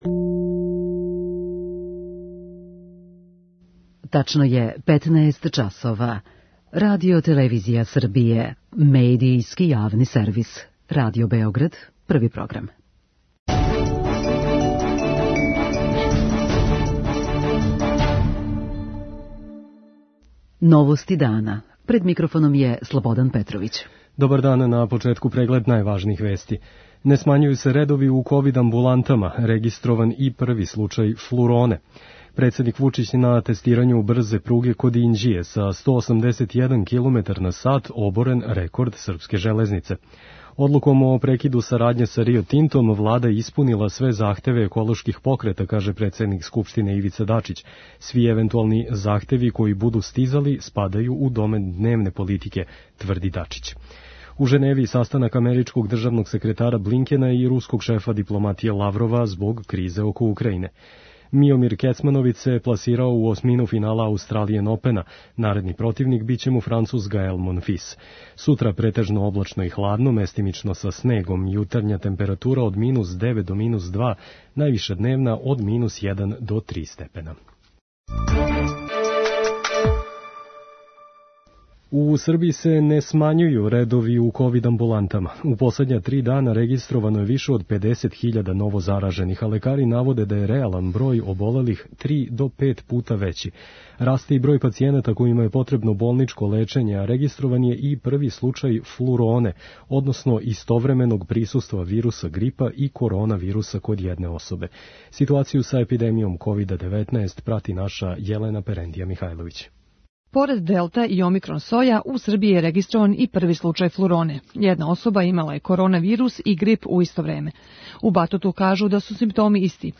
novosti2101.mp3